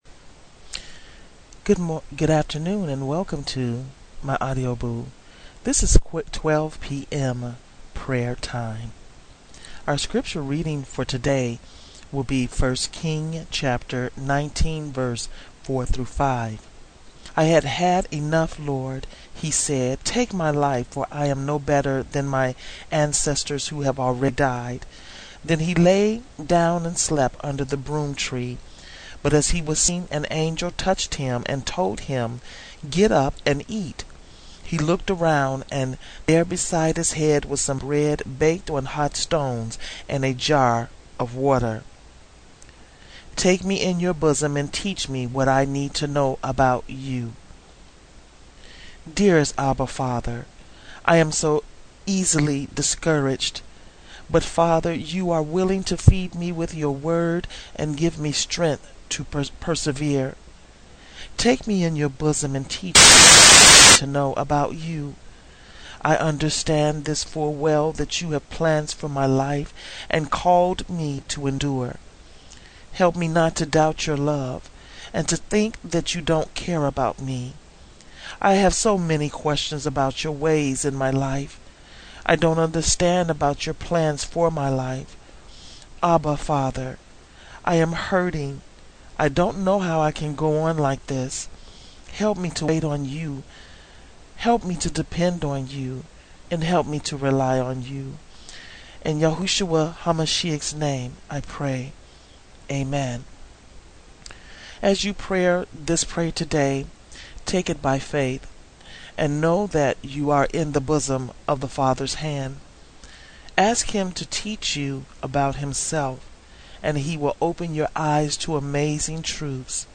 Evening prayer